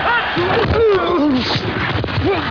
CRUNCH2.WAV